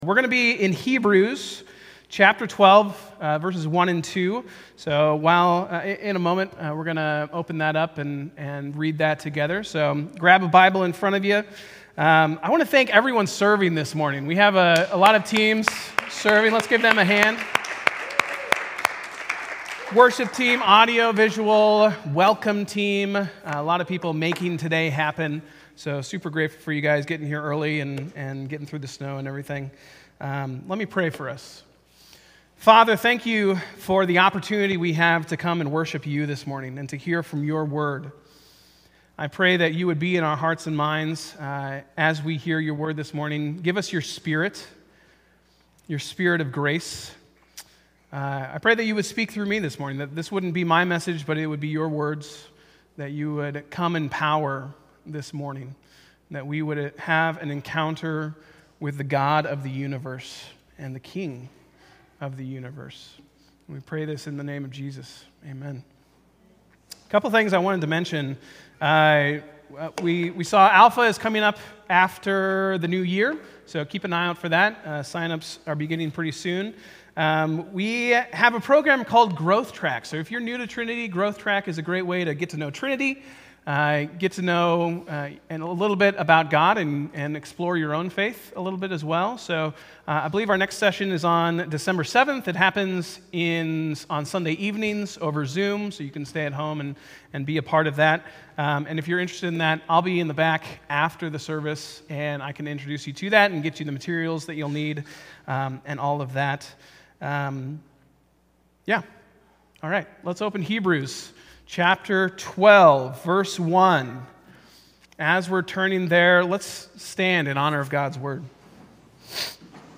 A message from the series "Standalone."